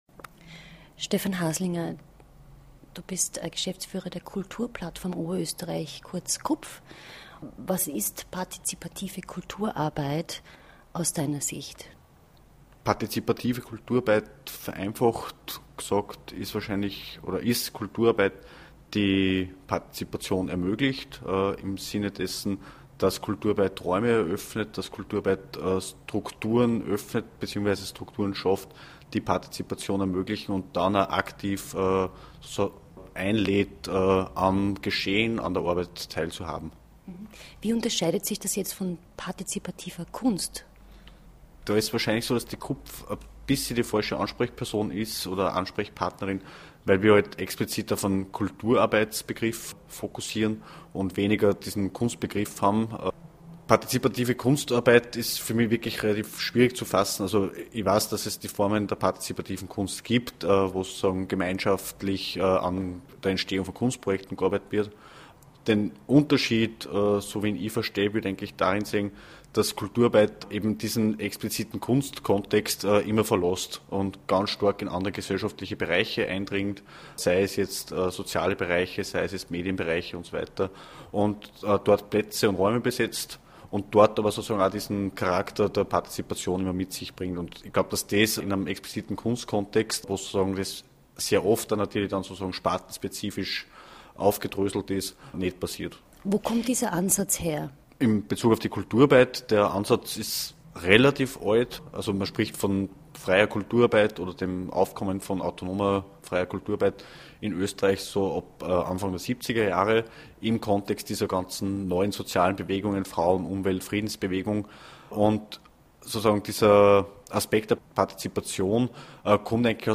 Live aus Auwiesen
Stereo 44kHz